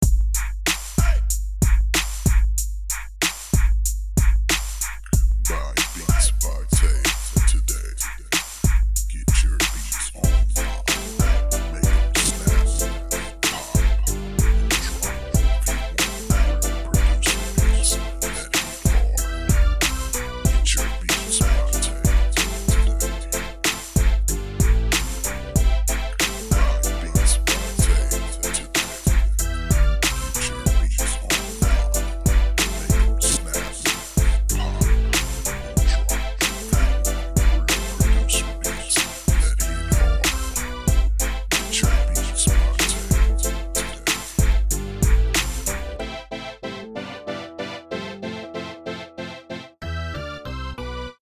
5 loop tracks